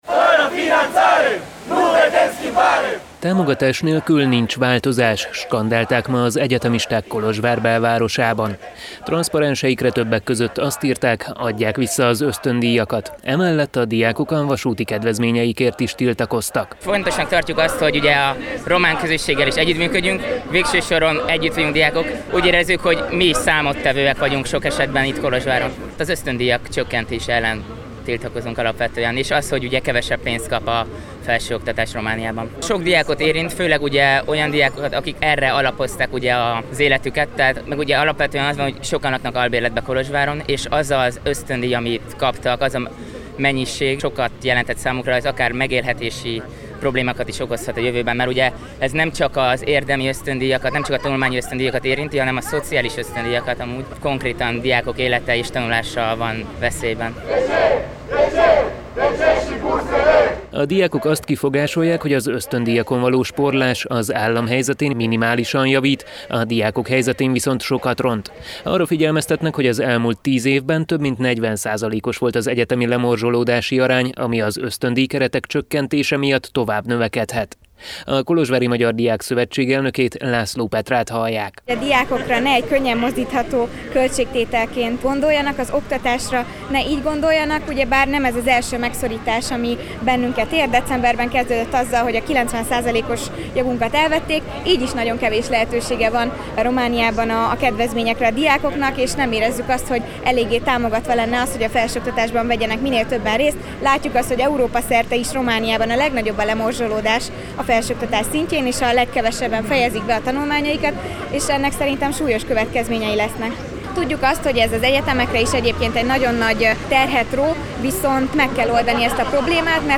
kolozsvári helyszíni összeállítása